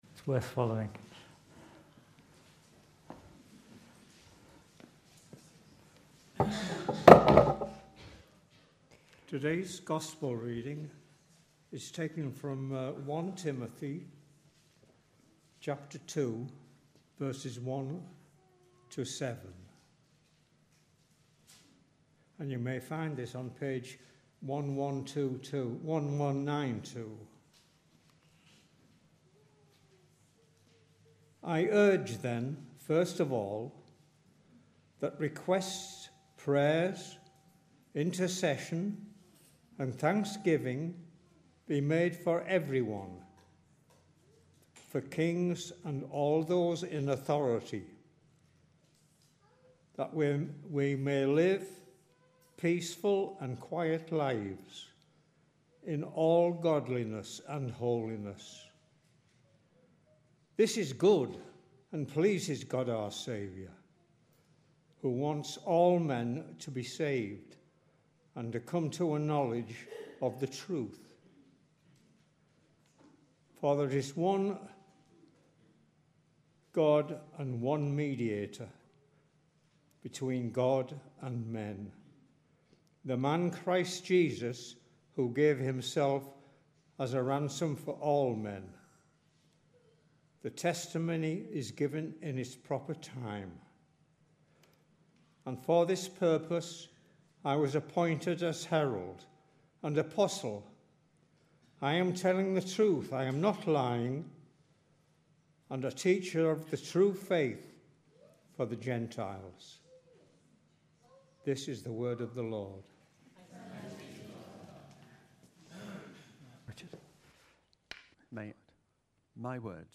Readings and Sermon for 12 November 2023 – Holy Trinity Church Cannes